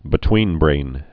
(bĭ-twēnbrān)